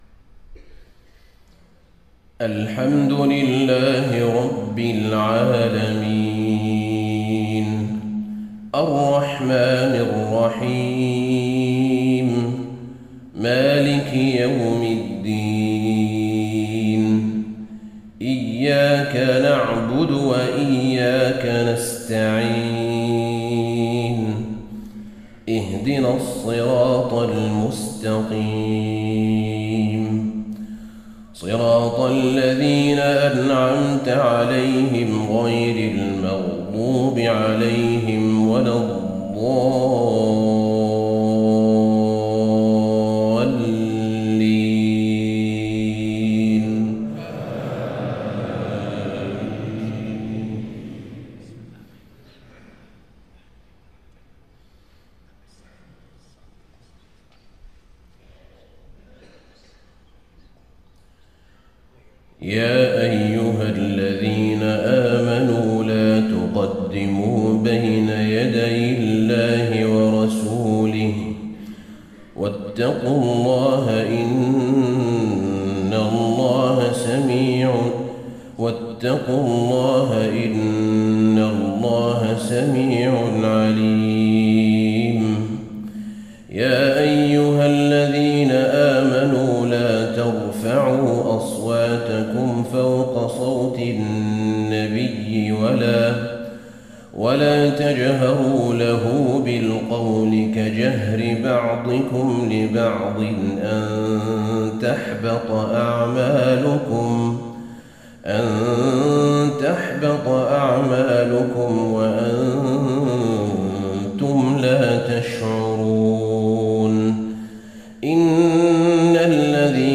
صلاة الفجر 16 شعبان 1435 سورة الحجرات كاملة > 1435 🕌 > الفروض - تلاوات الحرمين